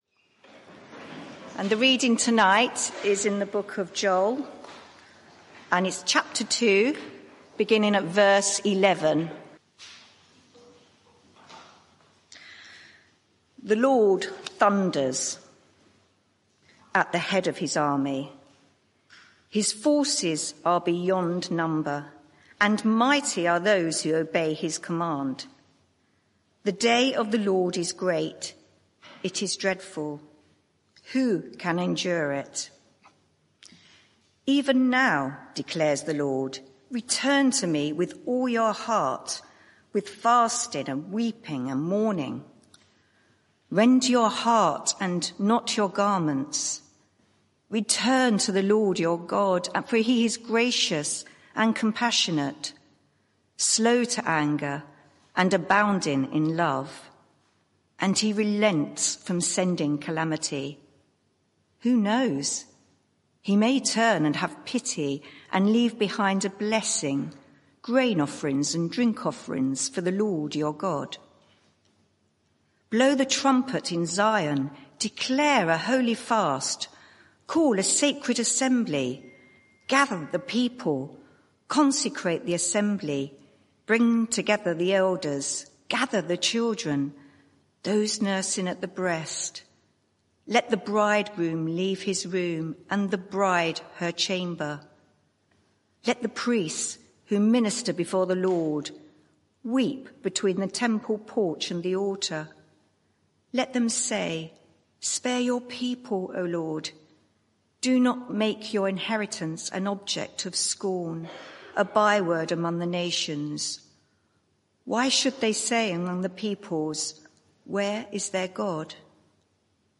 Media for 6:30pm Service on Sun 19th Nov 2023 18:30 Speaker
Sermon (audio) Search the media library There are recordings here going back several years.